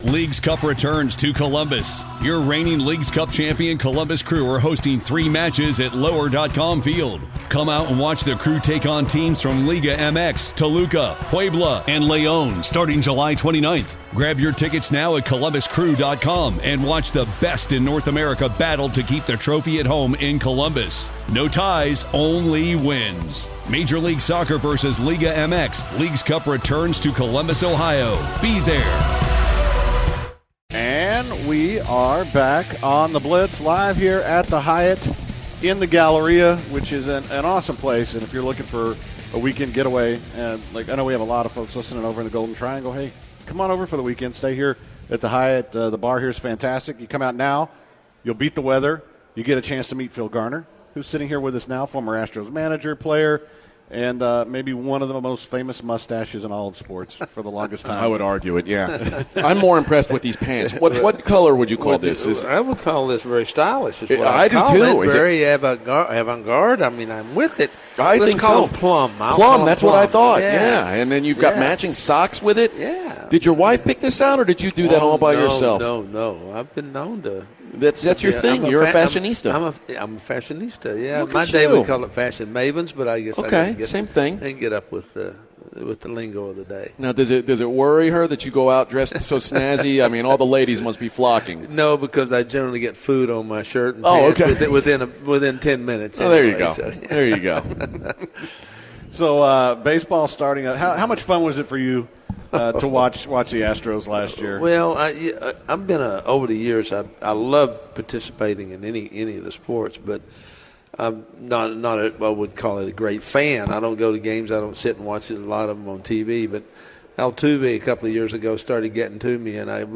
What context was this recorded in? at the Hyatt Regency